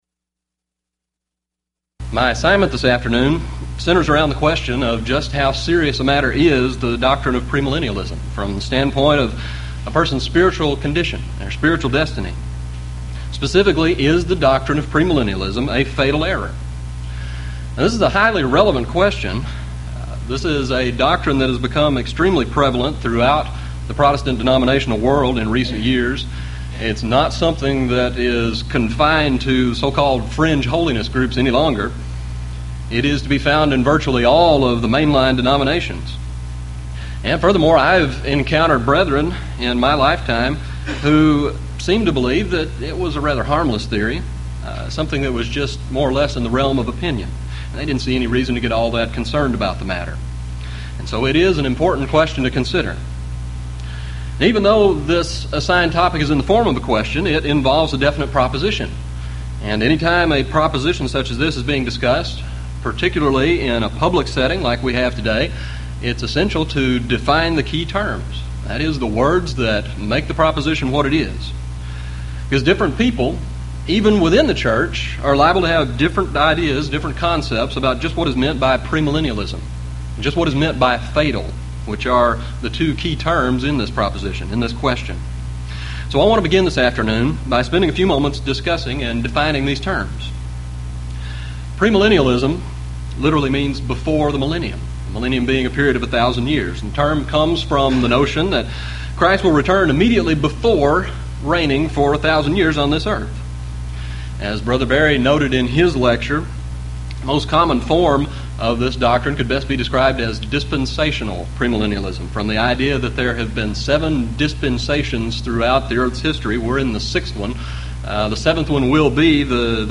Series: Houston College of the Bible Lectures Event: 1997 HCB Lectures